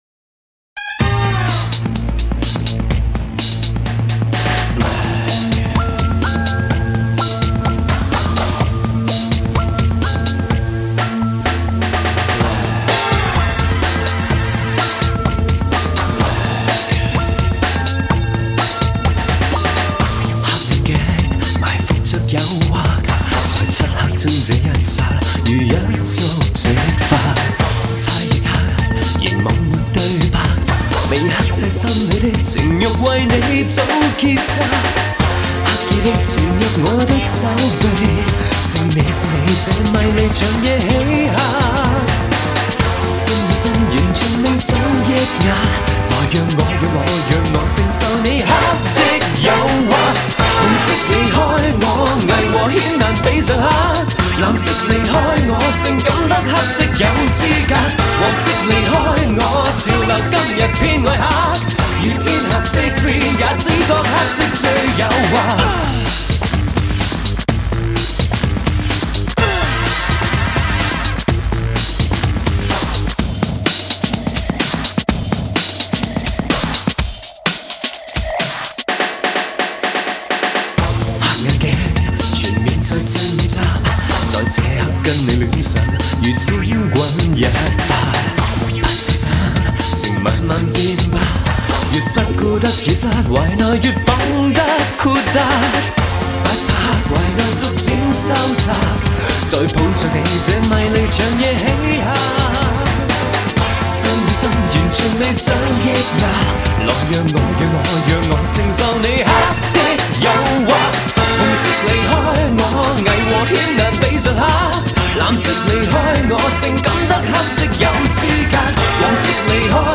歌曲过门的 RAP饶舌特别出色，使得歌曲平添了一层性感和时尚的色彩。
这首歌旋律平缓，曲子所要表达主题情绪是悲痛节奏沉重，歌曲色彩朦胧。